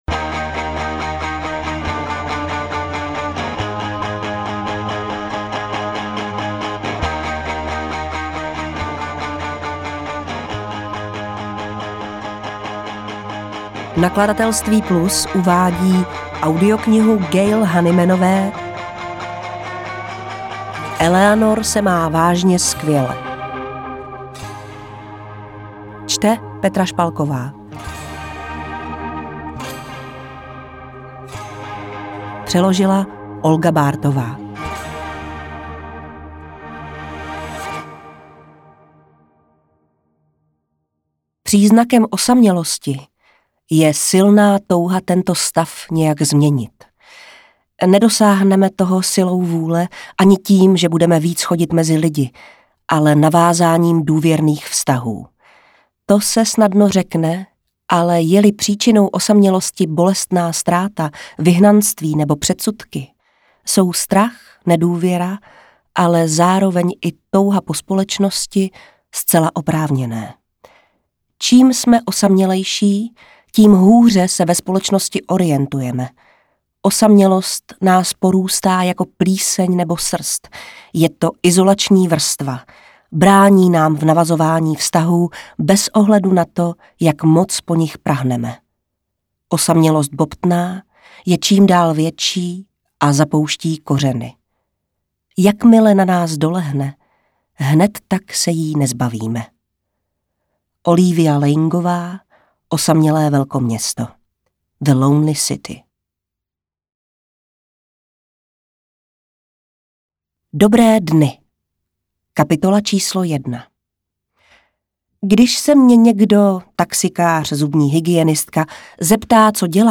Interpret:  Petra Špalková
AudioKniha ke stažení, 42 x mp3, délka 12 hod. 11 min., velikost 1000,8 MB, česky